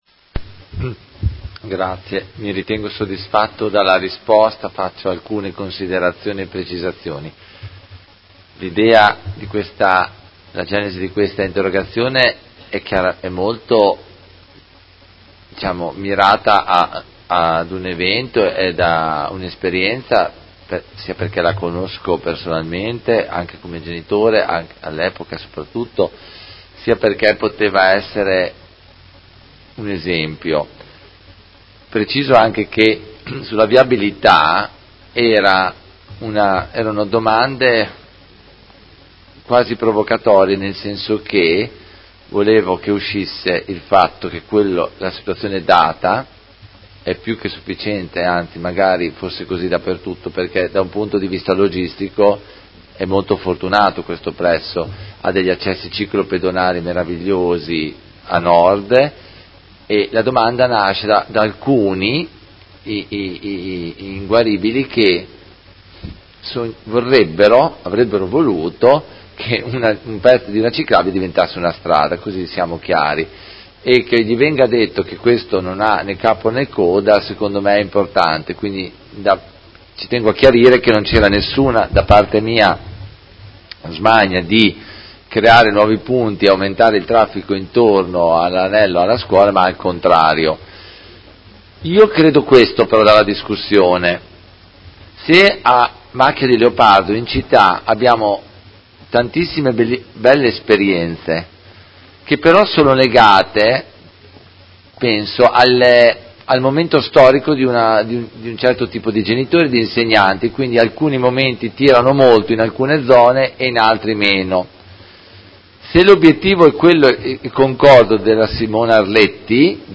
Seduta del 17/01/2019 Replica a risposta Assessora Filippi. Interrogazione dei Consiglieri Carpentieri e Baracchi (PD) avente per oggetto: Viabilità in Via Frescobaldi – accesso al Polo scolastico.